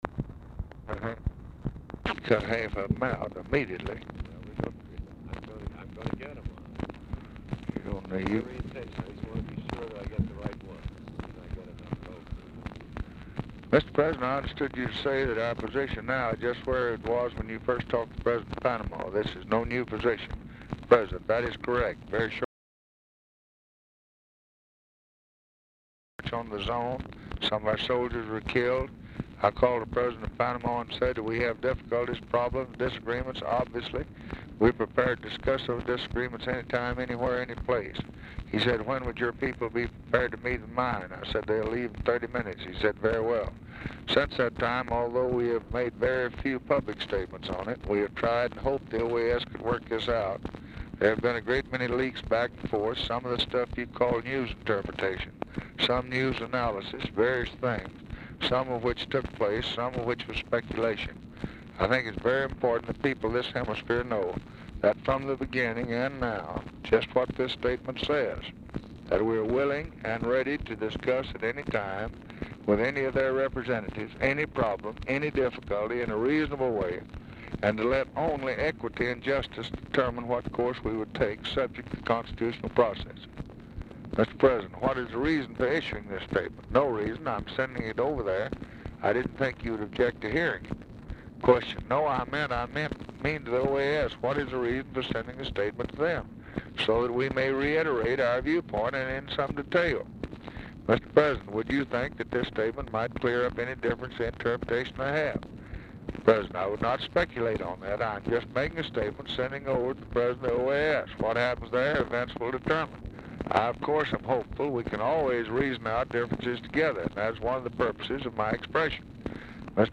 Telephone conversation # 2595, sound recording, LBJ and THOMAS MANN, 3/21/1964, 3:35PM | Discover LBJ
RECORDING STARTS AFTER CONVERSATION HAS BEGUN
Format Dictation belt
Oval Office or unknown location
Specific Item Type Telephone conversation